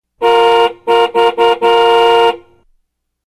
Tiếng Còi Xe Ô Tô Con (Nhạc Chuông)